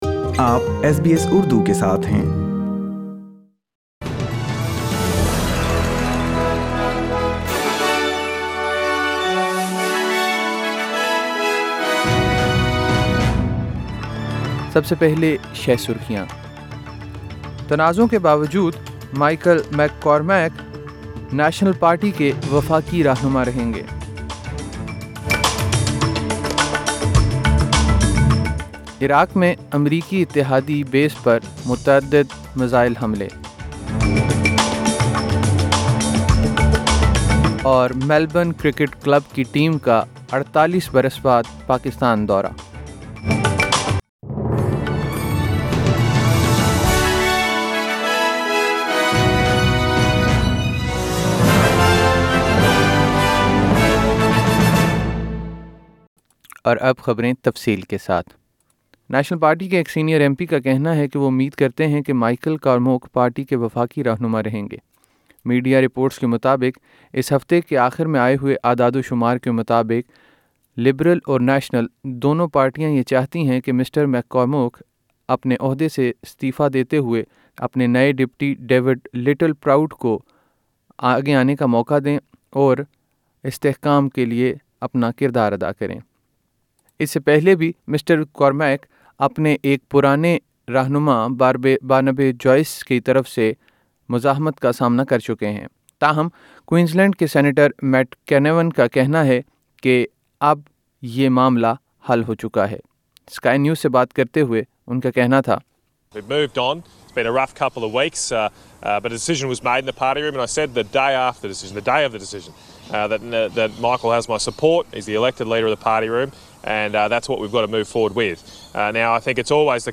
ایس بی ایس اردو خبریں ۱۷ فروری ۲۰۲۰